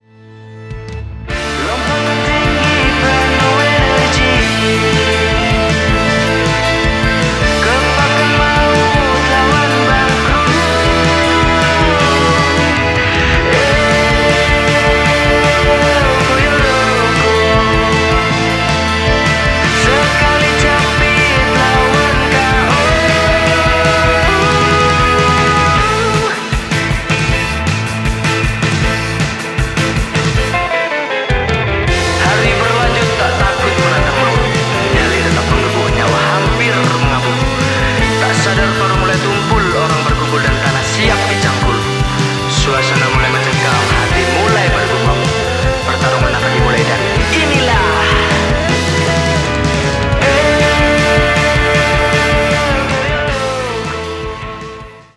band alternative rock